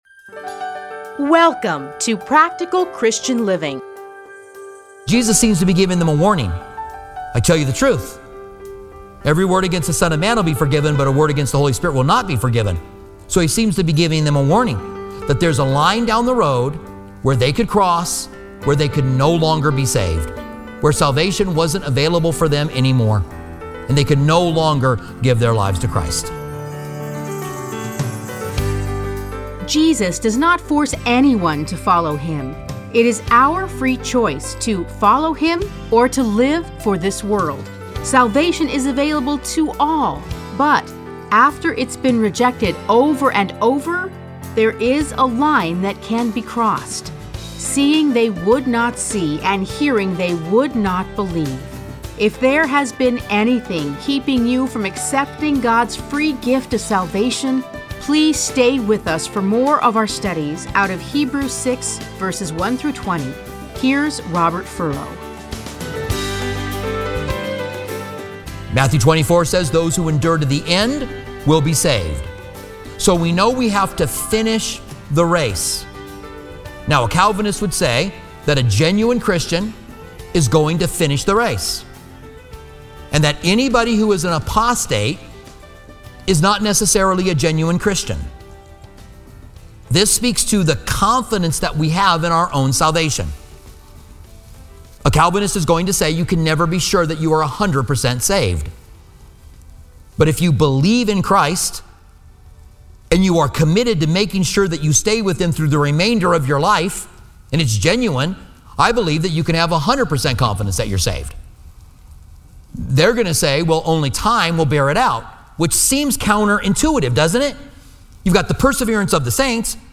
Listen to a teaching from Hebrews 6:1-20.